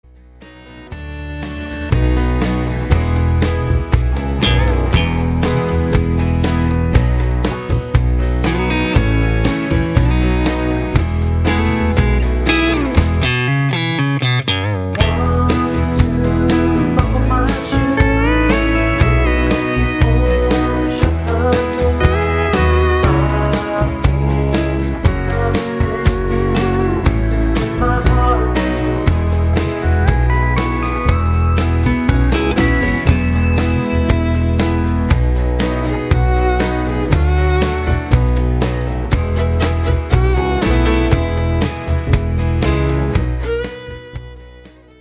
Midi Demo